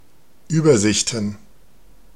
Ääntäminen
Ääntäminen Tuntematon aksentti: IPA: /yːbɐˌzɪçtn̩/ Haettu sana löytyi näillä lähdekielillä: saksa Käännöksiä ei löytynyt valitulle kohdekielelle. Übersichten on sanan Übersicht monikko.